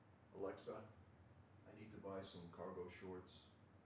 alexa-03.wav